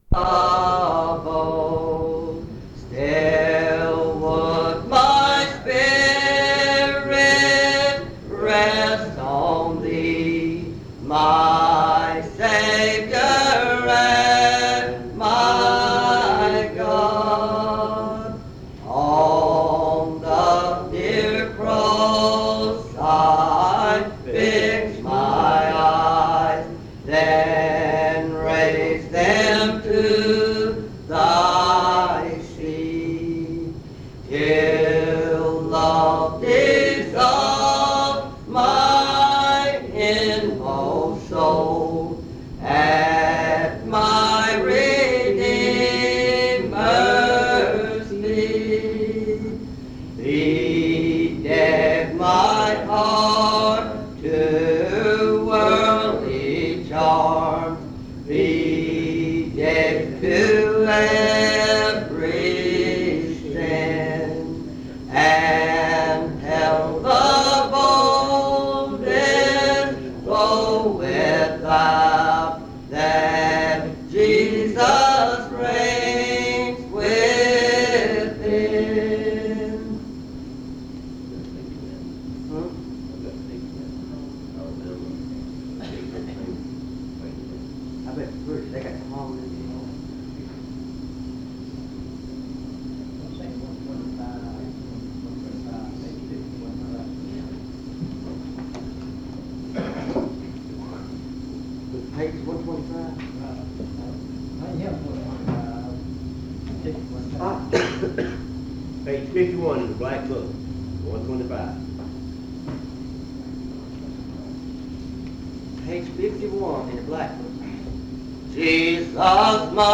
En Collection: Monticello Primitive Baptist Church audio recordings Miniatura Título Fecha de subida Visibilidad Acciones PBHLA-ACC.002_009-A-01.wav 2026-02-12 Descargar PBHLA-ACC.002_009-B-01.wav 2026-02-12 Descargar